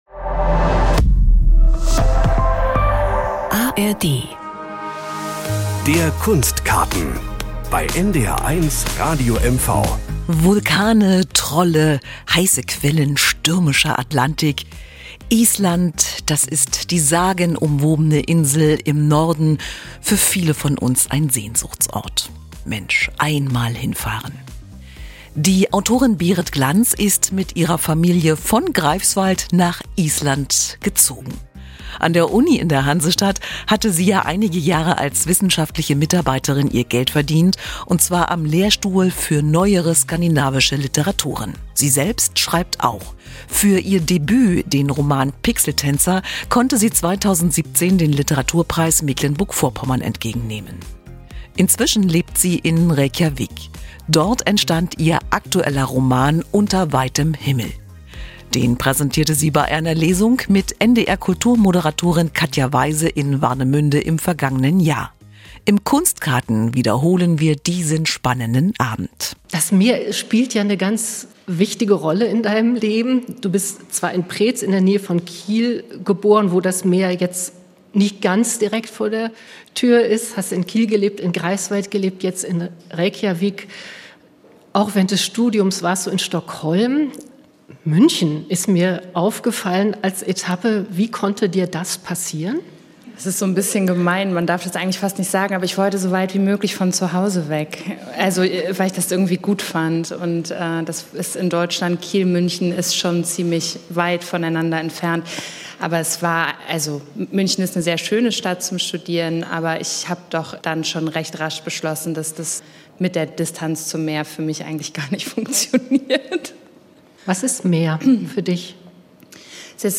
Im Rahmen von "Der Norden liest" wurde diese Sendung von NDR Kultur aufgezeichnet.